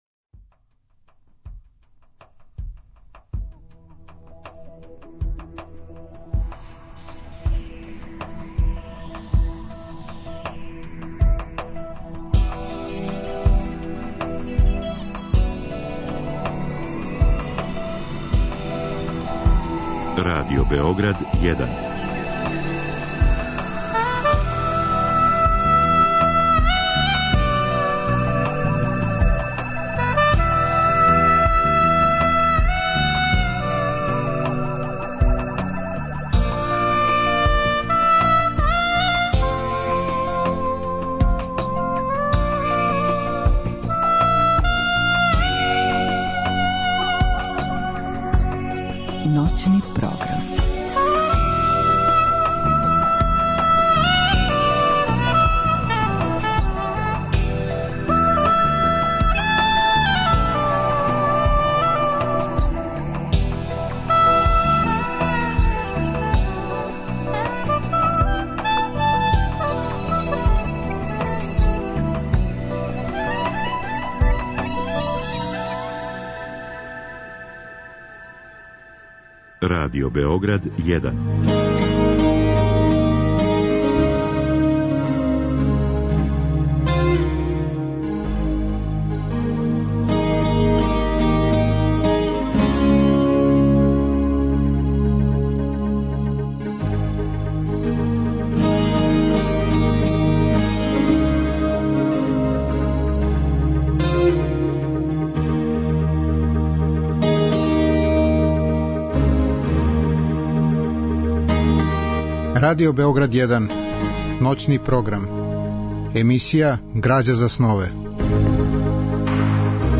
Разговор и добра музика требало би да кроз ову емисију и сами постану грађа за снове.
У 2. делу емисије, од 2 до 4 часа ујутро, слушаћемо одабране делове из радио-драма рађених по бајкама Ханса Кристијана Андерсена Ружно Паче, Девојчица са шибицама и Снежна краљица, као и делове из радио-адаптација српских народних приповедака Баш Челик и Златна јабука и девет пауница.